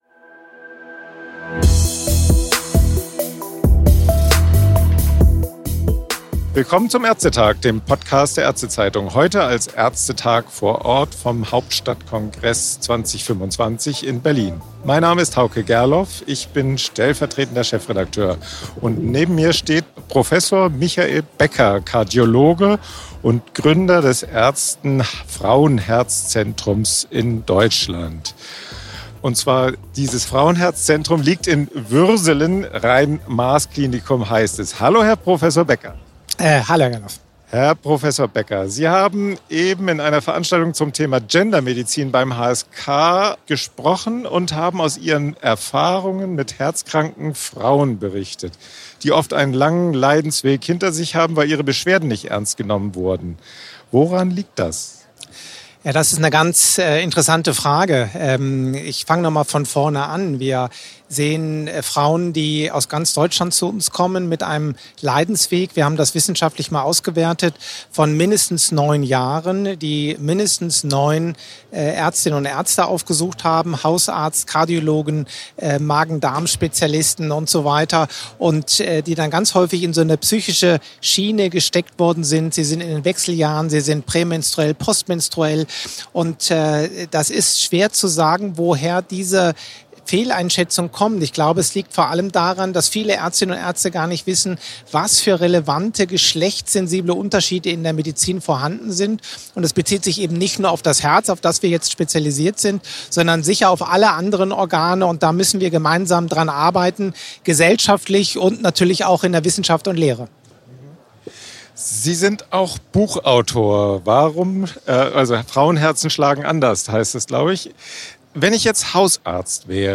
im „ÄrzteTag vor Ort“ vom Hauptstadtkongress in Berlin.